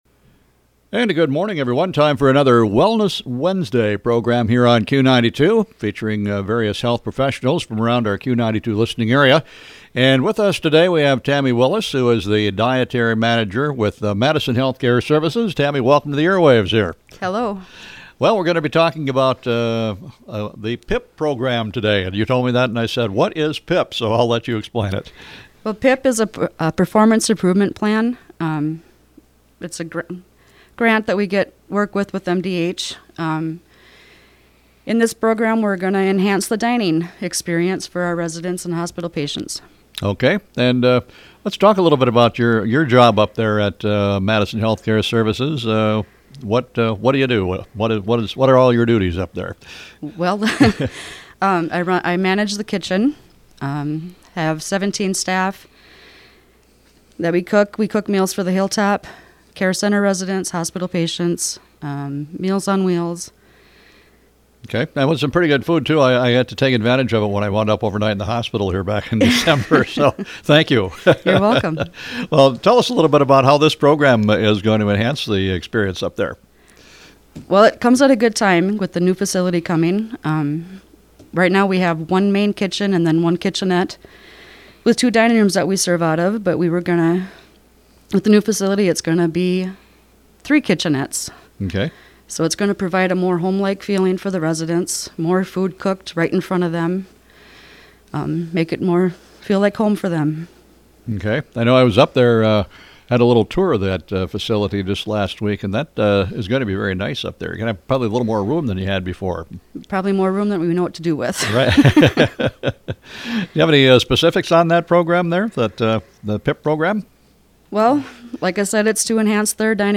mhcs interview